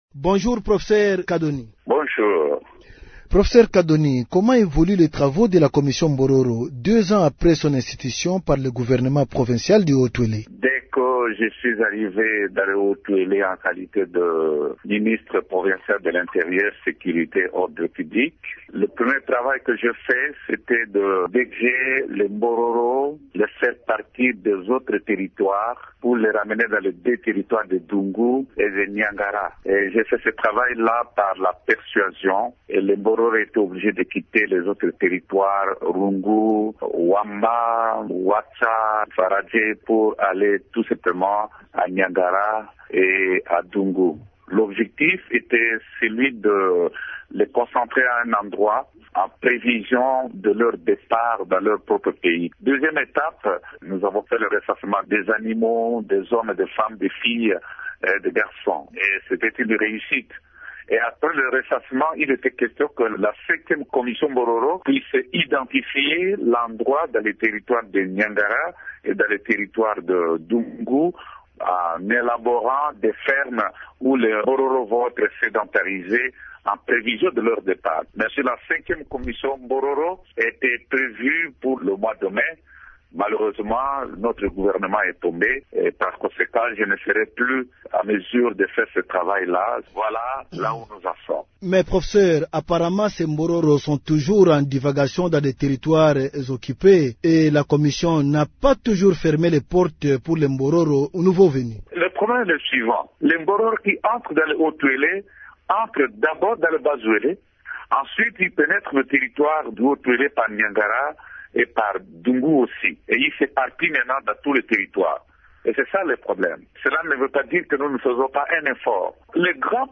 Le Professeur Kadoni Kalayingu est ministre provincial honoraire de l’Intérieur et président de ladite commission.